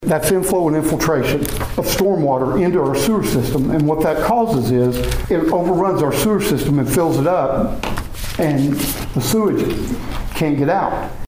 During Tuesday evening's city council meeting, Pawhuska City Manager Jerry Eubanks highlighted a problem with the old infrastructure in the town's sewer system.